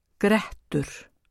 uttale